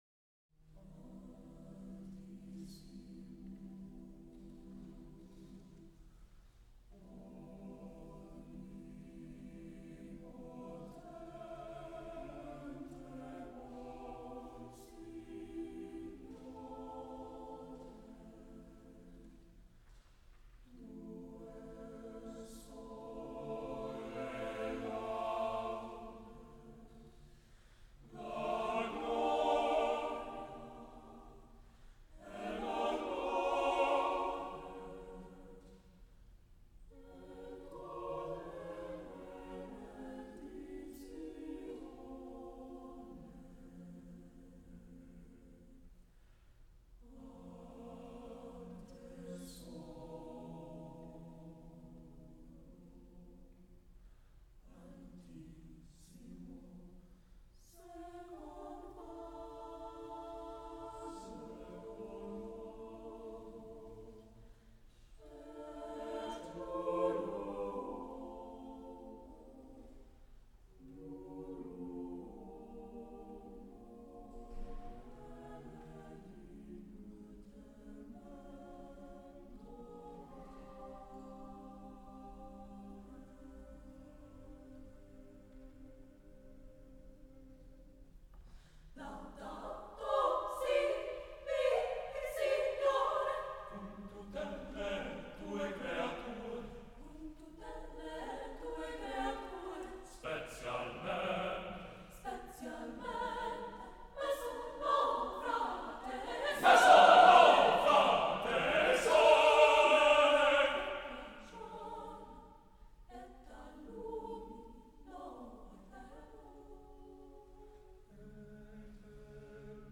csucs - Music from the CSU Chamber Singers while I was a member
NCCO Inaugural Conference Closing Concer